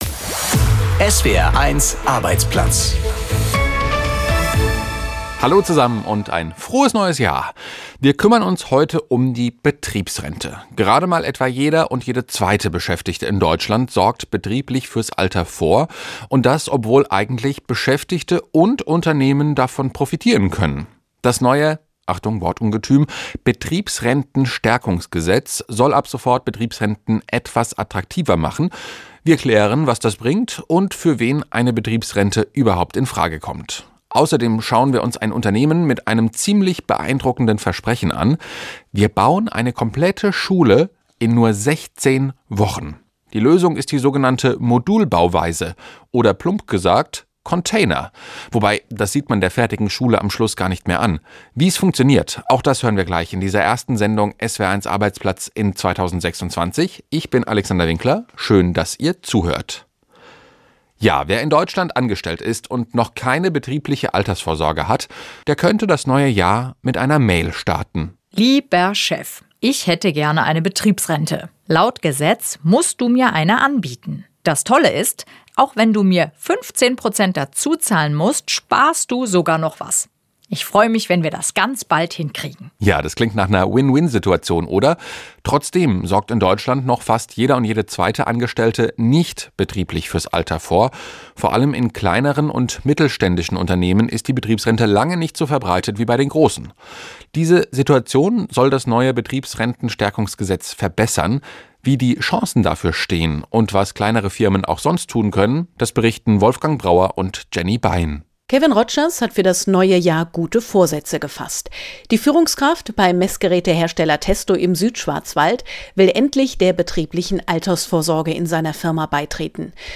Beschreibung vor 3 Monaten Ist das neue Gesetz jetzt tatsächlich der Durchbruch? Und für wen kommt eine Betriebsrente sonst noch in Frage? ++ Interview